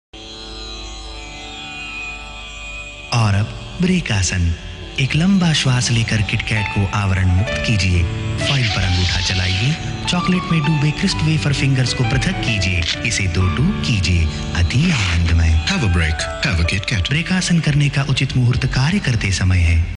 File Category : Free mobile ringtones > > Sms ringtones
File Type : Tv confectionery ads